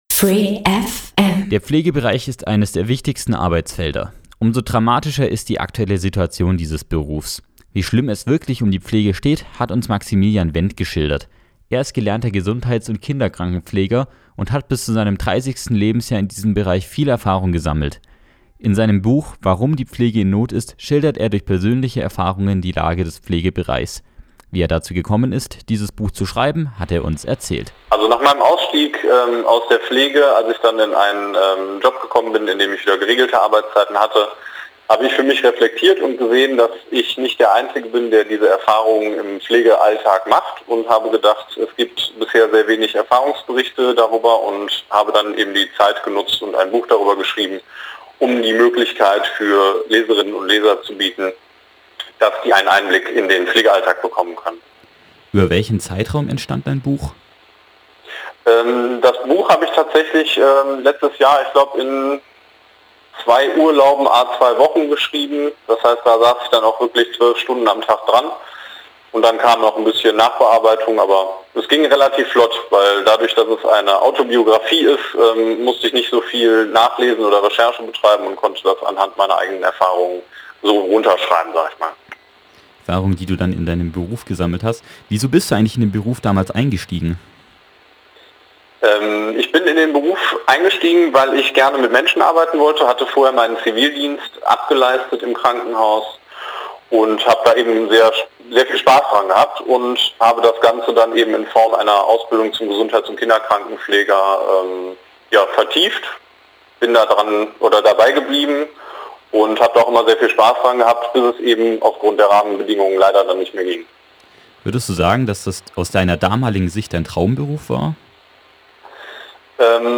Radio free FM